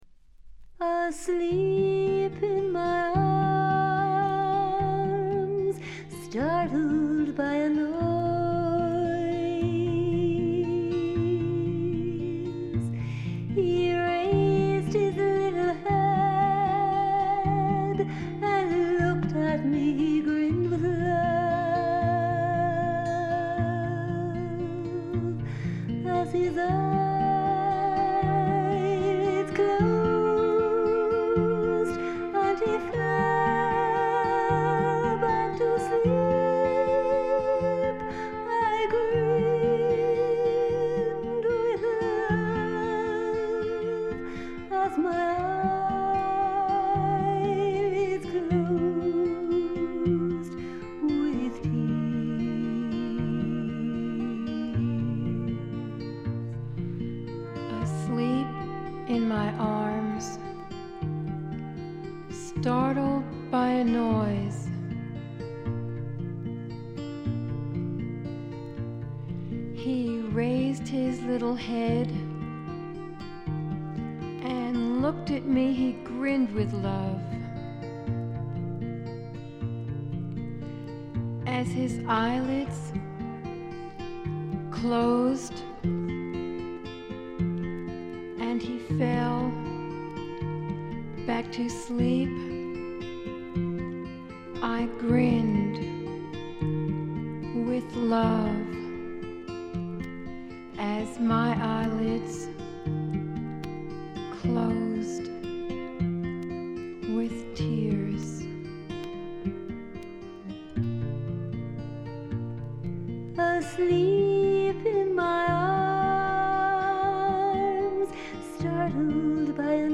少し舌足らずな声で歌う楚々とした魅力が最高です。
基本は控えめなバックが付くフォークロック。
試聴曲は現品からの取り込み音源です。